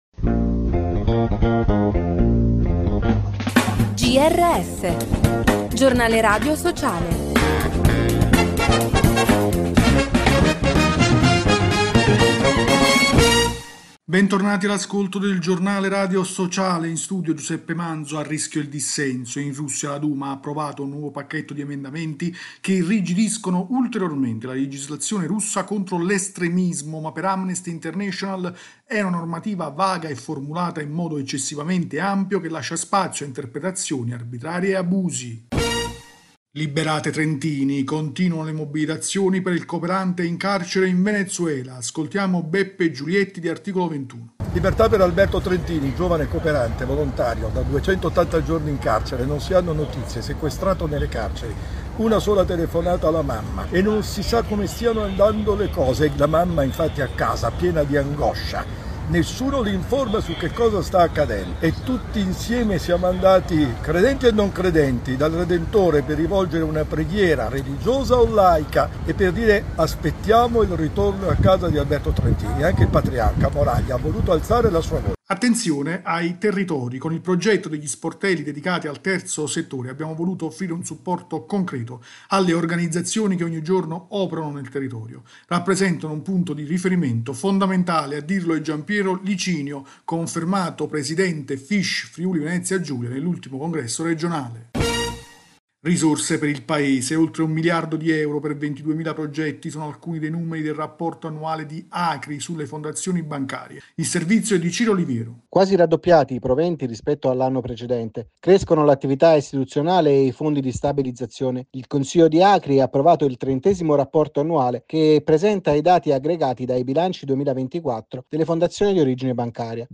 Bentornati all’ascolto del Giornale radio sociale. In studio